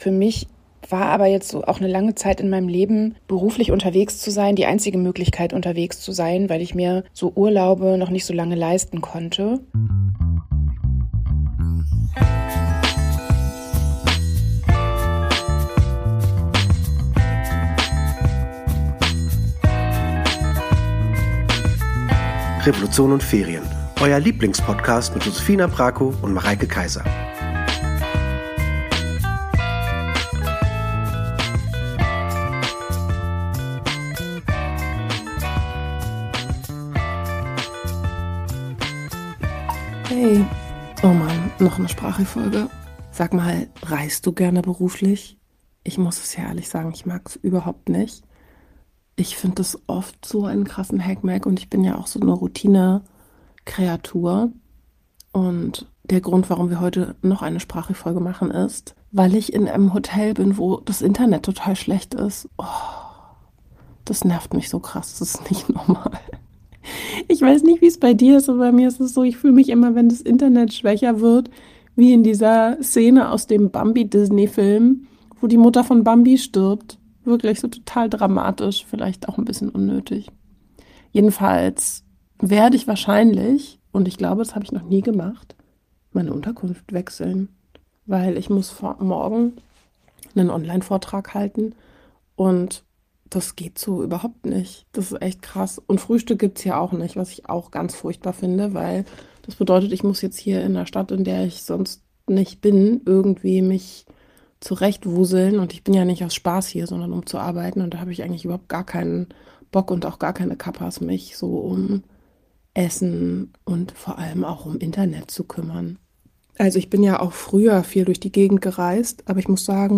Also nochmal eine Sprachi-Folge. Es geht um berufliche Reisen und private, also irgendwie auch um Ferien.